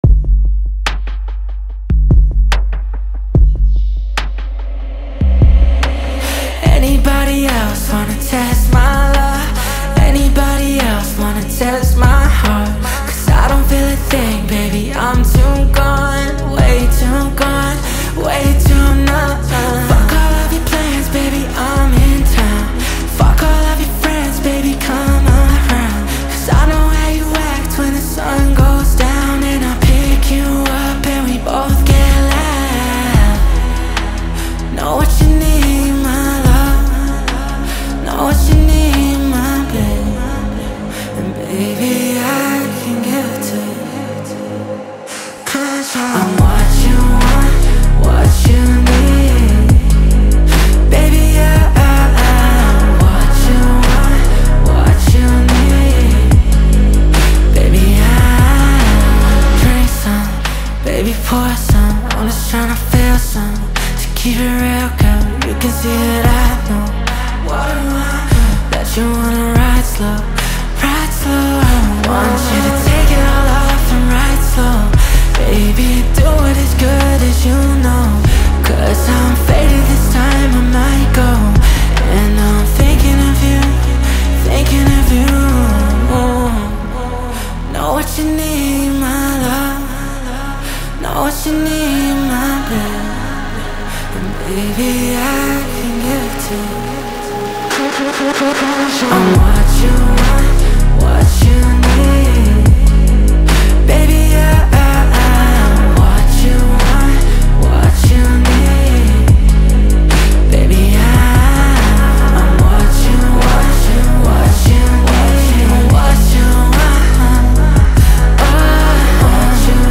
сочетающему элементы R&B и поп.